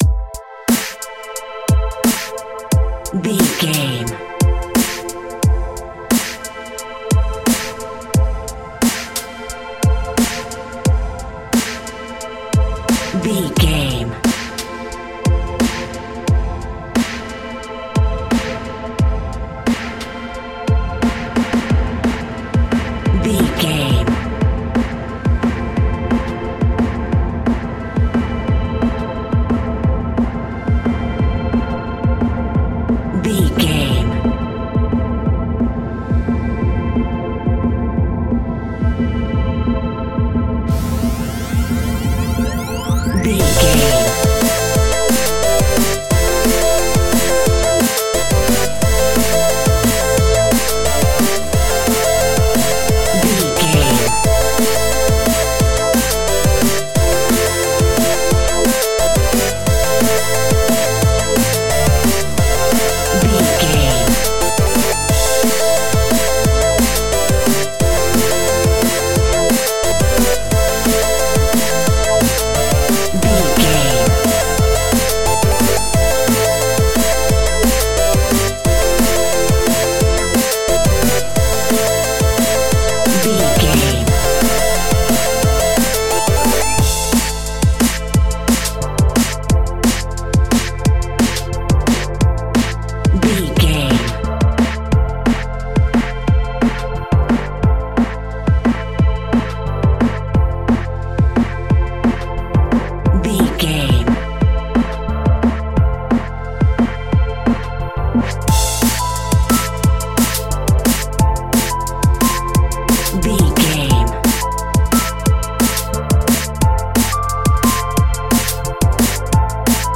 Epic / Action
Fast paced
Aeolian/Minor
aggressive
dark
driving
energetic
futuristic
synthesiser
drum machine
electronic
sub bass